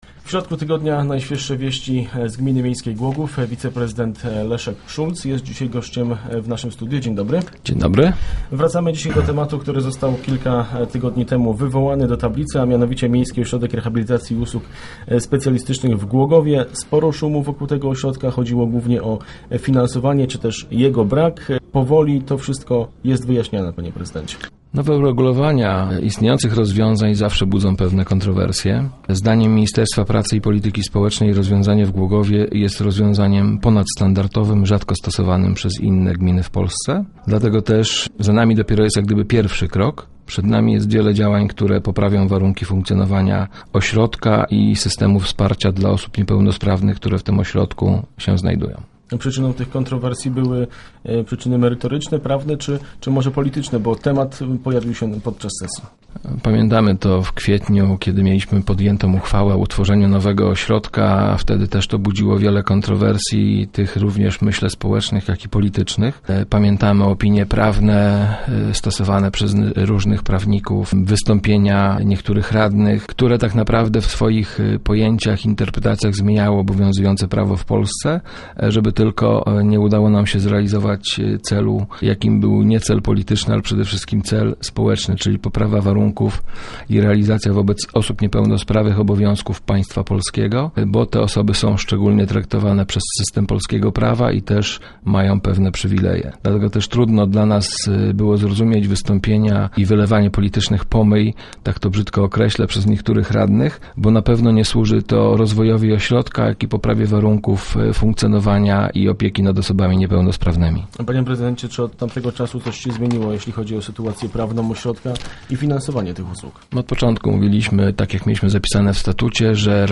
W środowych Rozmowach Elki z wiceprezydentem Głogowa Leszkiem Szulcem poruszaliśmy temat funkcjonowania Miejskiego Ośrodka Rehabilitacji i Usług Specjalistycznych, wokół którego narosło ostatnio wiele kontrowersji.